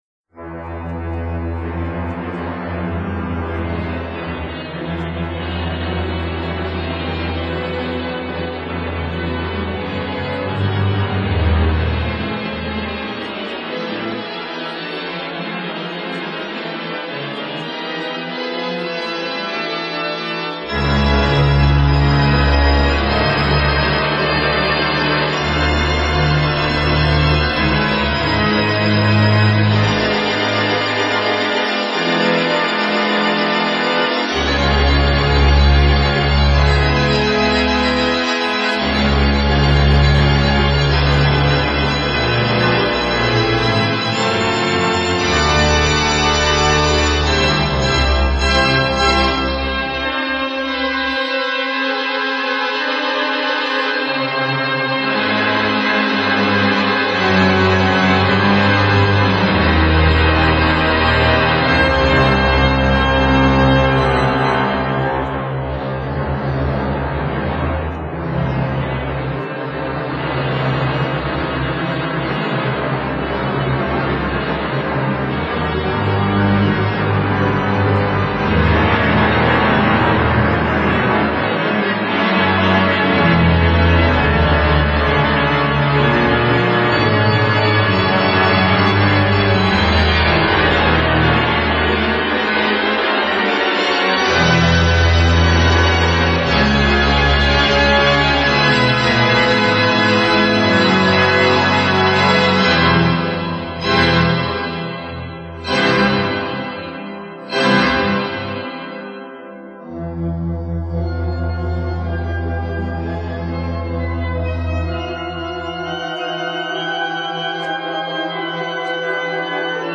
au grand-orgue de l’Abbaye aux Hommes de Caen